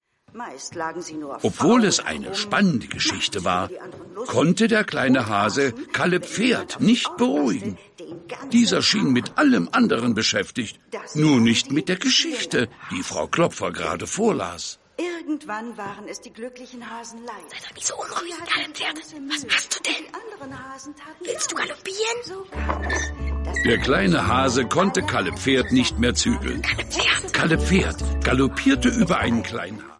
Ravensburger Der kleine Hase kommt in den Kindergarten ✔ tiptoi® Hörbuch ab 3 Jahren ✔ Jetzt online herunterladen!